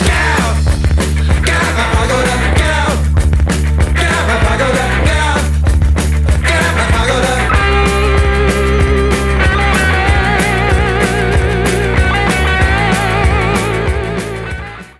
at Air Studios in London in 1976/1977